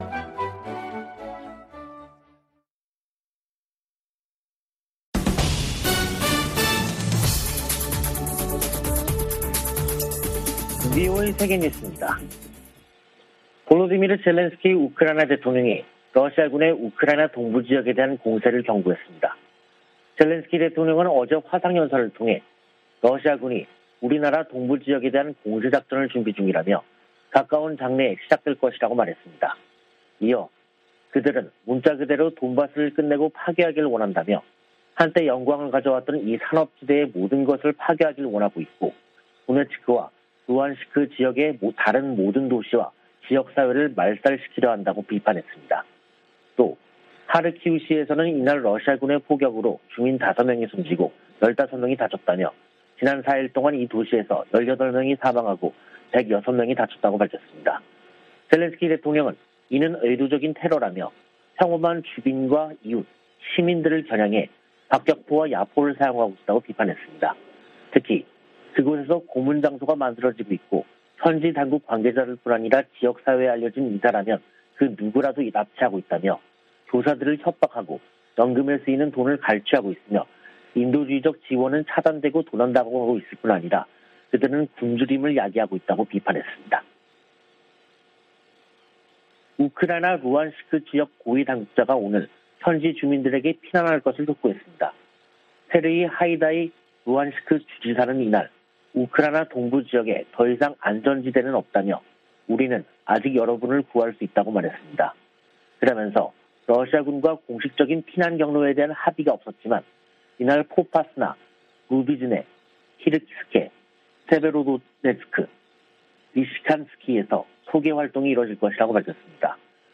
VOA 한국어 간판 뉴스 프로그램 '뉴스 투데이', 2022년 4월 18일 2부 방송입니다. 북한 대외 관영 매체들은 김정은 국무위원장 참관 아래 신형 전술유도무기 시험발사가 성공했다고 보도했습니다. 미국령 괌 당국은 북한이 미상의 발사체를 쏜데 따라 역내 상황을 주시 중이라고 발표했다가 5시간 만에 철회했습니다. 미 국무부는 국제사회가 북한의 제재 회피를 막기위해 광범위한 협력을 하고 있으며 특히 대량살상무기관련 밀수 단속에 집중하고 있다고 밝혔습니다.